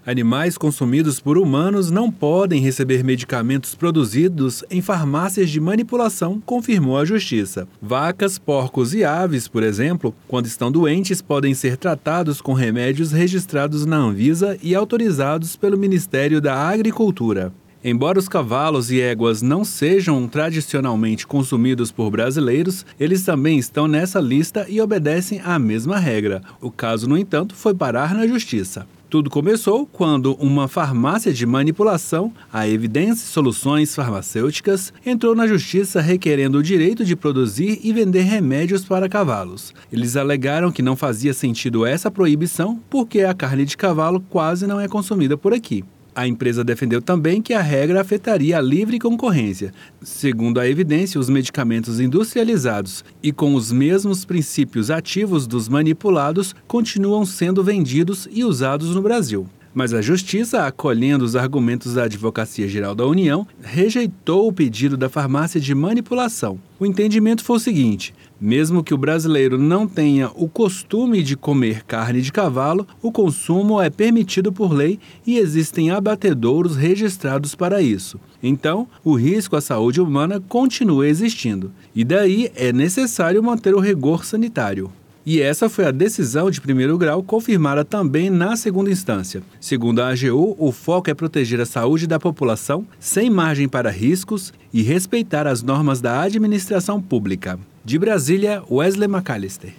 Saiba mais no boletim